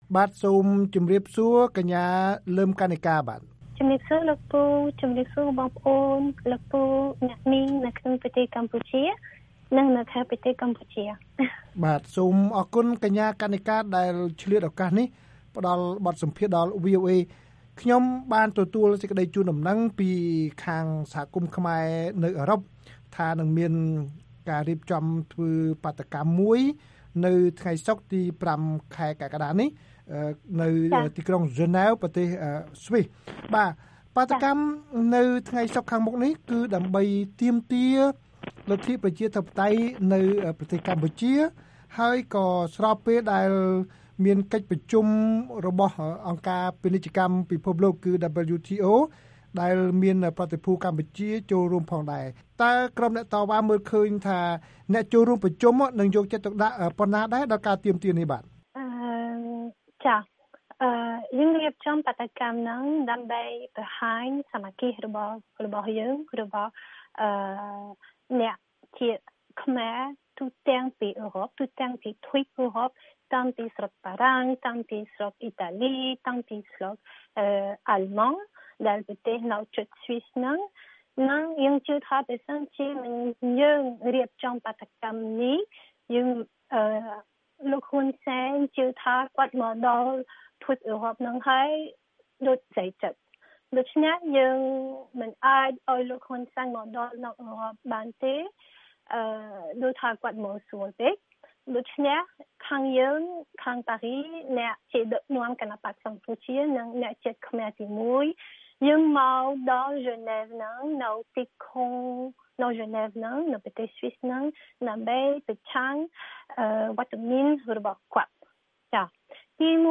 បទសម្ភាសន៍ VOA៖ បាតុកម្មទាមទារលទ្ធិប្រជាធិបតេយ្យពេលលោក ហ៊ុន សែន នៅស្វីស